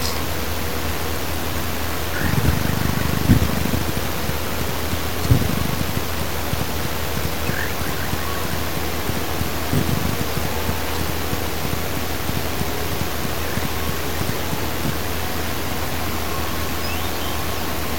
Bacurau-chintã (Setopagis parvula)
Nome em Inglês: Little Nightjar
Condição: Selvagem
Certeza: Gravado Vocal
ATAJACAMINOS-CHICO.mp3